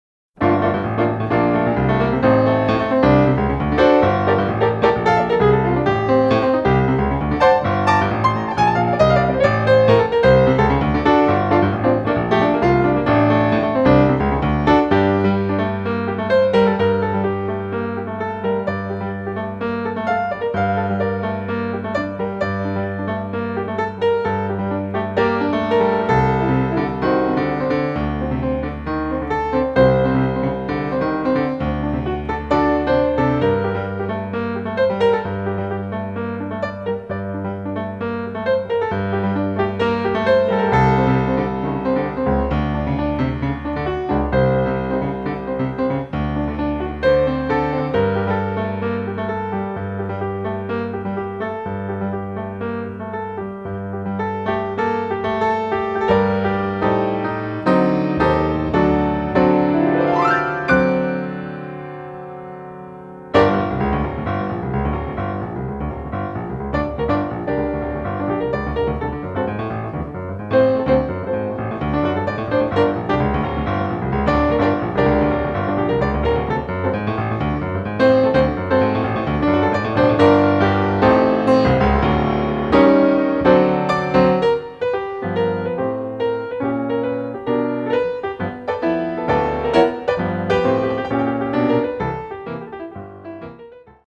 Performed on the concert Bosendorfer grand piano
at the UMKC Center For the Performing Arts-White Hall.
(piano)